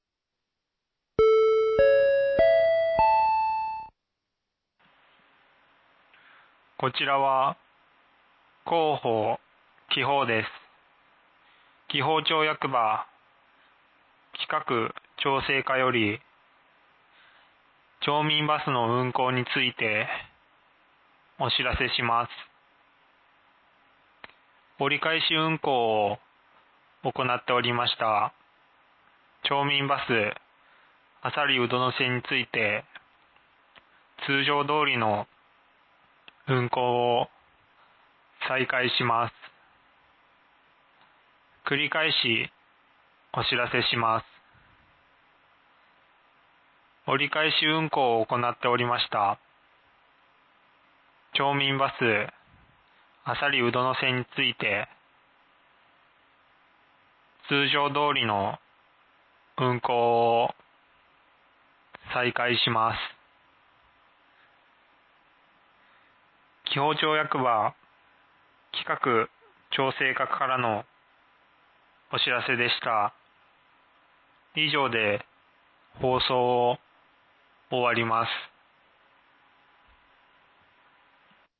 放送音声